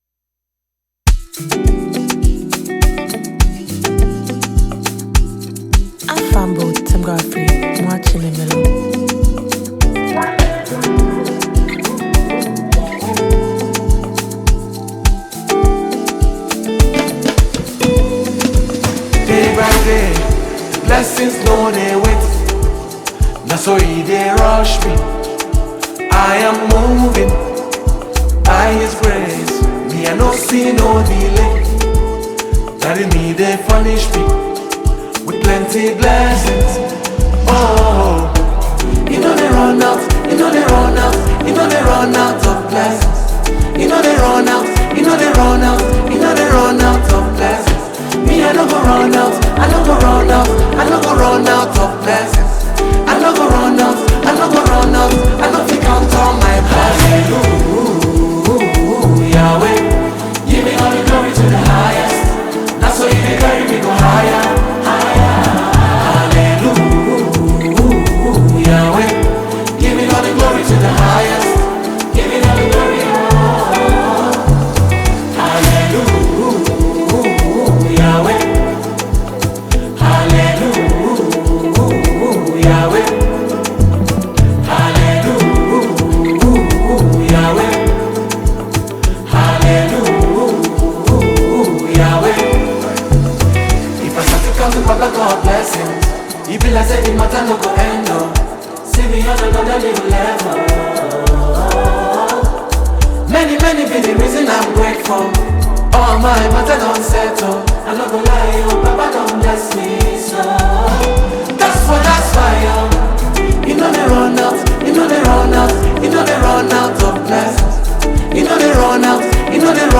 • Genre: Gospel / Contemporary Praise